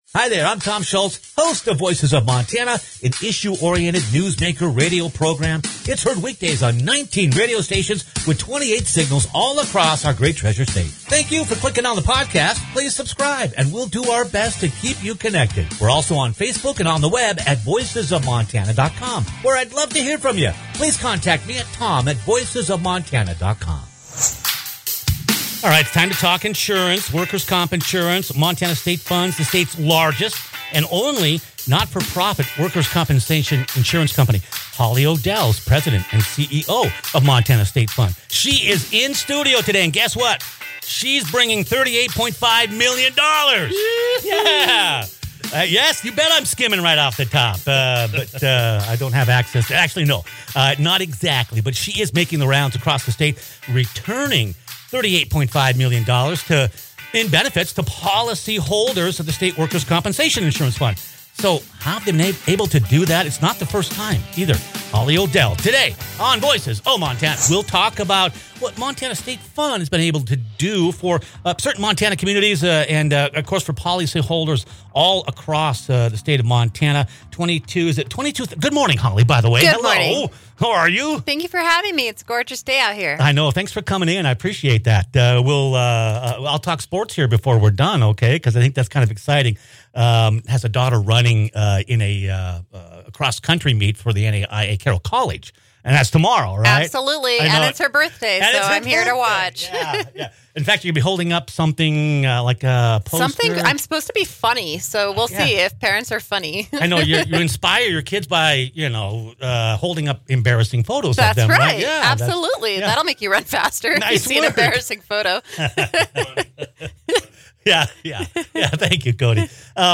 She's visiting our studio bringing $38.5 million dollars with her….